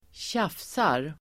Ladda ner uttalet
tjafsa verb, fuss, babbleGrammatikkommentar: A &Uttal: [²tj'af:sar] Böjningar: tjafsade, tjafsat, tjafsa, tjafsarDefinition: prata onödigt och tröttande, tjata